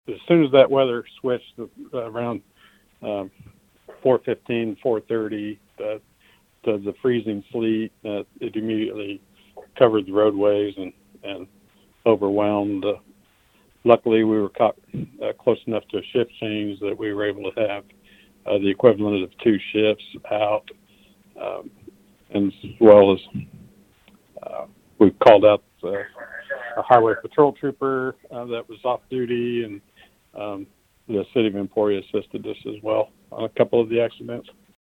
Lyon County Sheriff Jeff Cope says things got extremely busy as heavy sleet overspread the county.